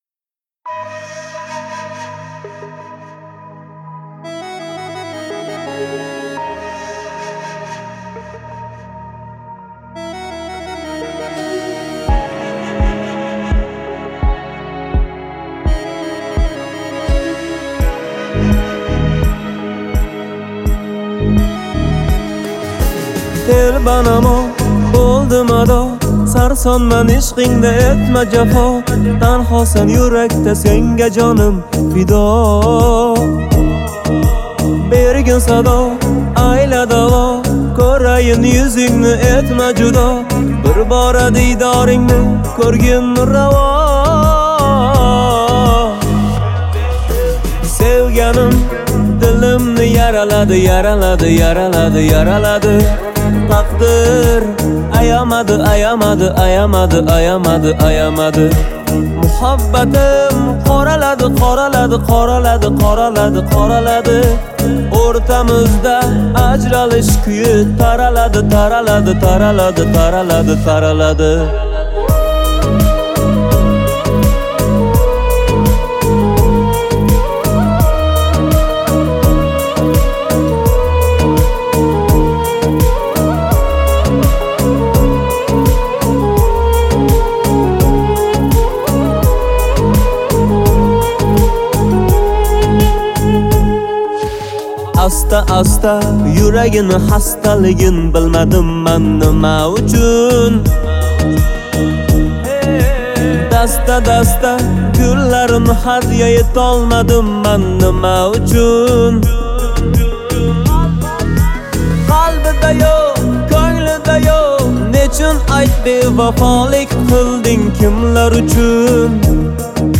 яркая и эмоциональная композиция
выполненная в жанре узбекской поп-музыки.